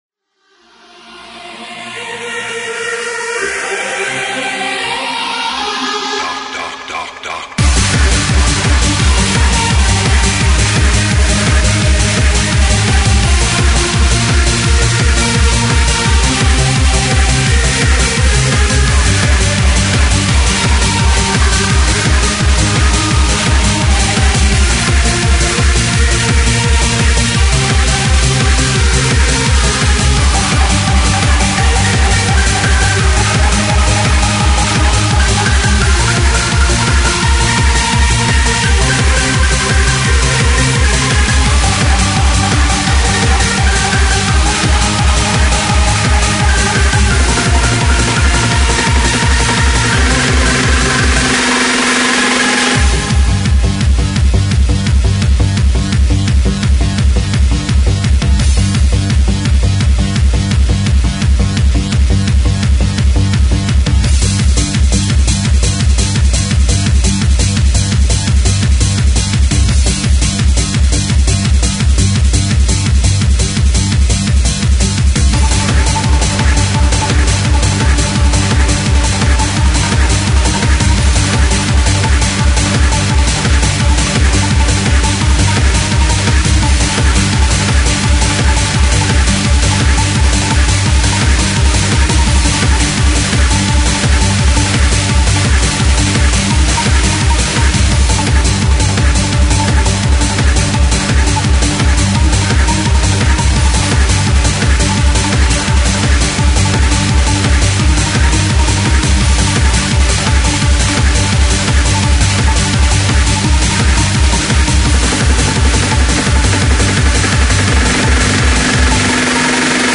Freeform/Hardcore